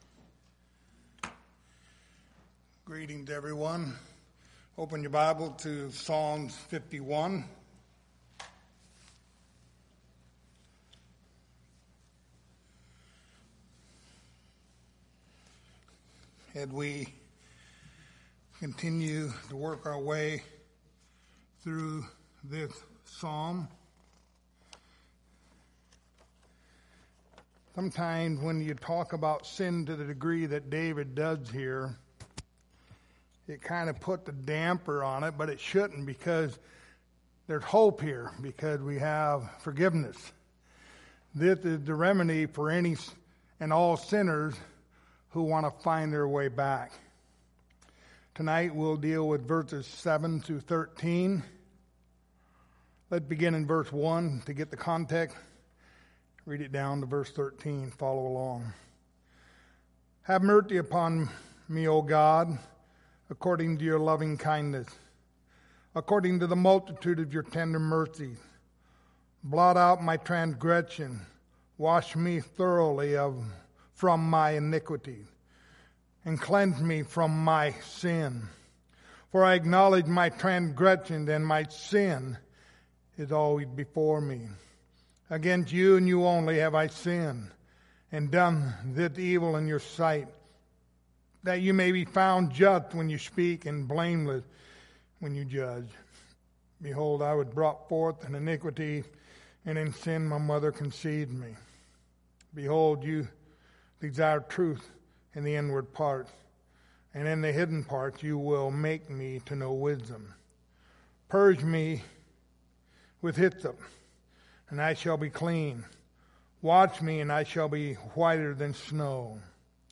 Passage: Psalm 51:7-13 Service Type: Sunday Evening Topics